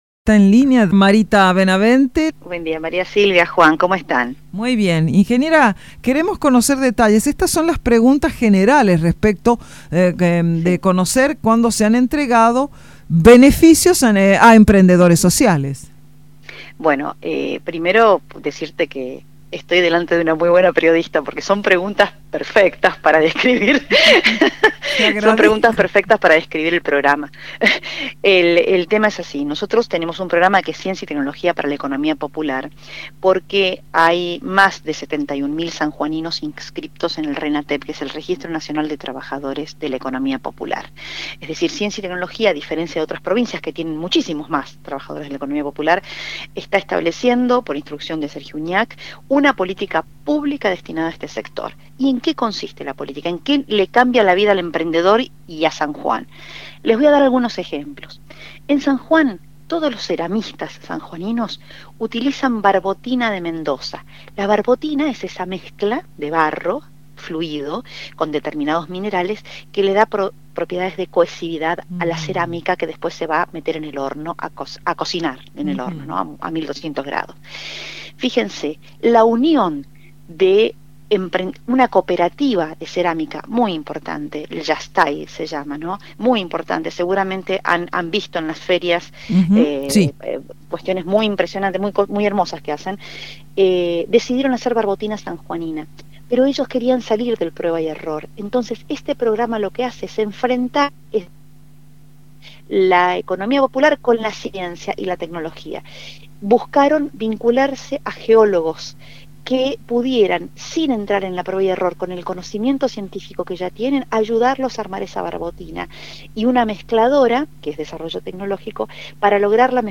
Este lunes se realizó la entrega de resoluciones a emprendedores sociales de la provincia, en el marco del Programa “Ciencia y Tecnología para la Economía Popular, CITEP”. Por este motivo, Marita Benavente, secretaria de Ciencia, Tecnología e Innovación de San Juan, estuvo en los micrófonos de Radio Sarmiento para brindar detalles.